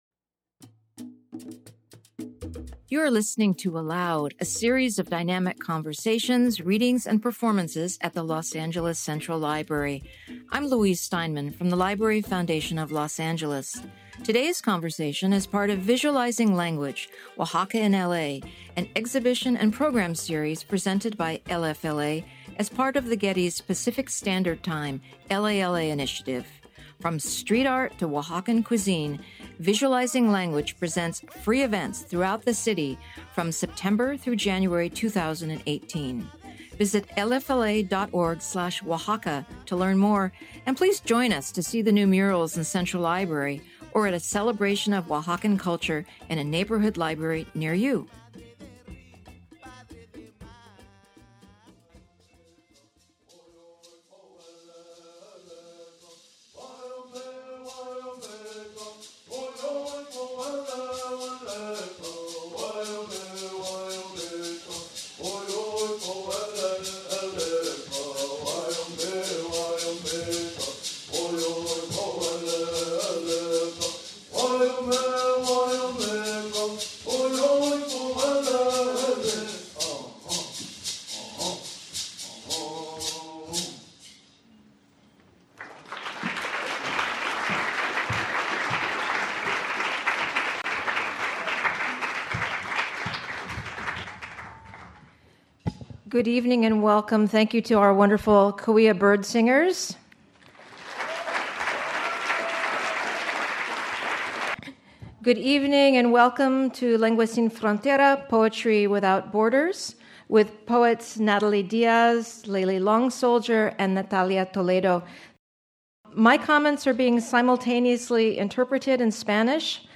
A Reading
Each poet will read from their distinctive work that moves across many languages and lands, exploring what it means to be an indigenous woman writer in today’s world.
Simultaneous interpretation was provided by Antena Los Ángeles.